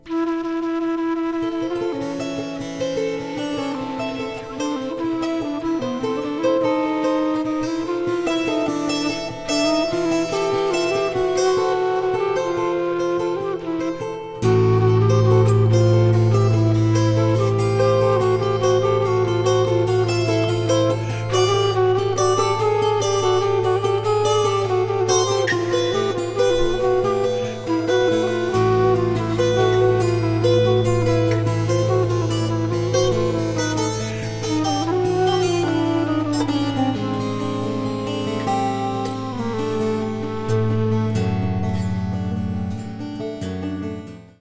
Tenor and Soprano saxophones, Alto flute